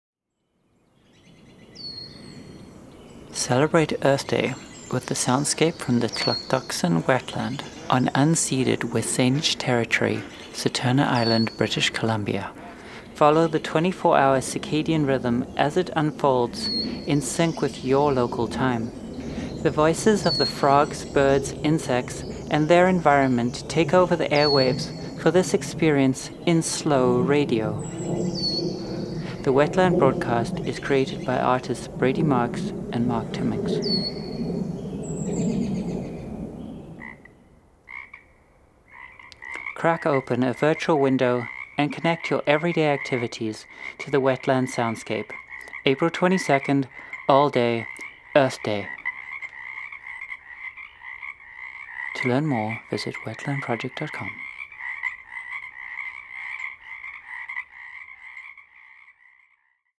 webSYNradio est heureuse de participer à la 10e édition du projet WETLAND : slow radio, field recording, écologie.
Since its inauguration on Vancouver Co-op Radio in 2017, listening to the 24-hour soundscape from the ṮEḴTEḴSEN marsh in W̱SÁNEĆ territory (Saturna Island, British Columbia, Canada) has grown into an Earth Day tradition heard on 57 radio stations and via the web in over 50 countries.
Fill the airwaves with the sounds of birds, frogs, insects and airplanes.
Wetland-Project-2026-audio-promo.mp3